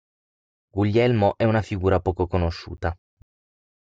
Pronounced as (IPA) /ˈpɔ.ko/